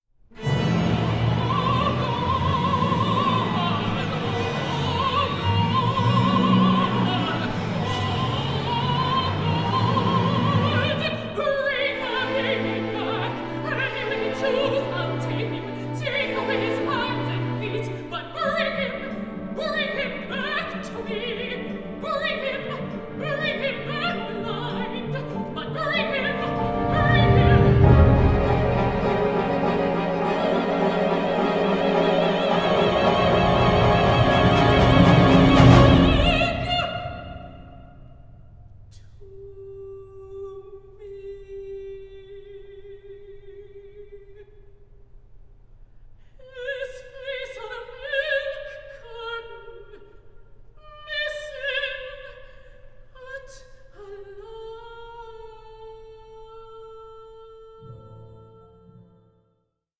a contemporary opera on racial injustice in the US today